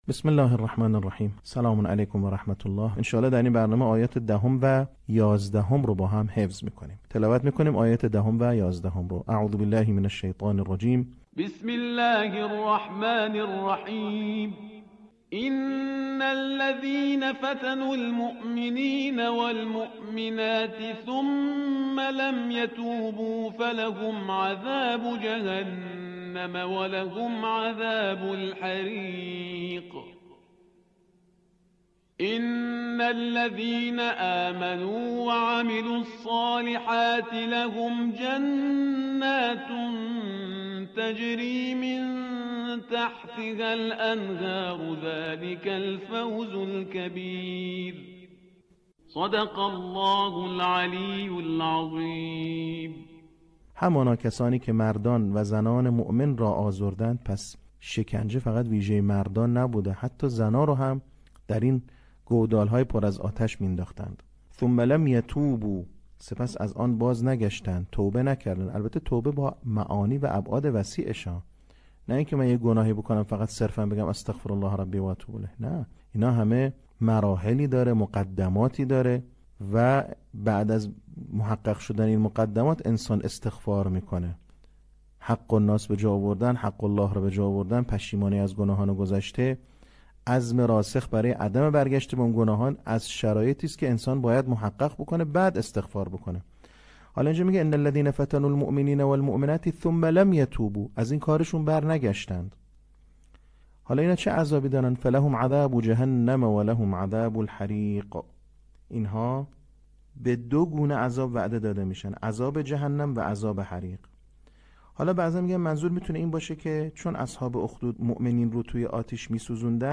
صوت | بخش سوم آموزش حفظ سوره بروج